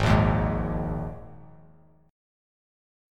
G#sus2 Chord